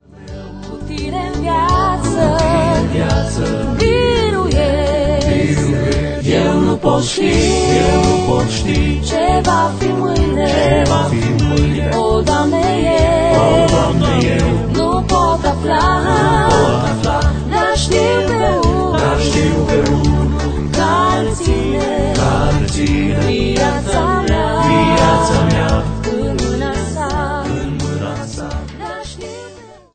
Personalitate, forta, dinamism, energie si originalitate.